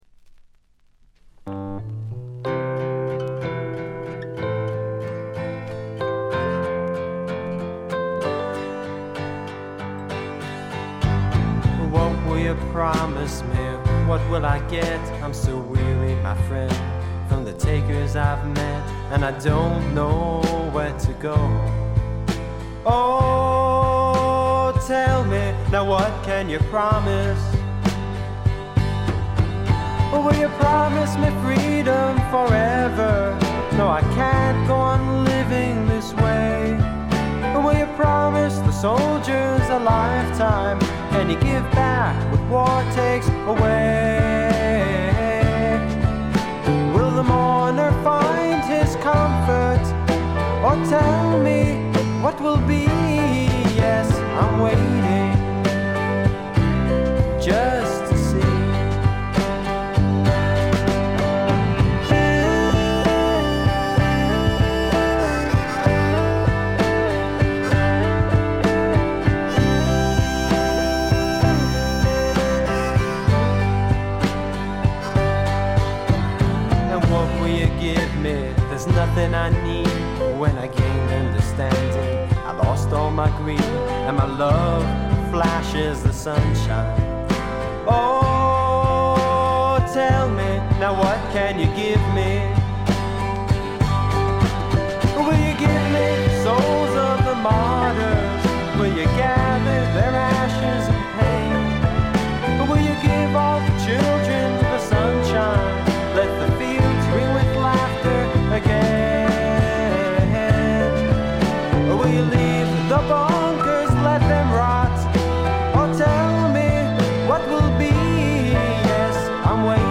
ほとんどノイズ感無し。
演奏、歌ともに実にしっかりとしていてメジャー級の85点作品。
試聴曲は現品からの取り込み音源です。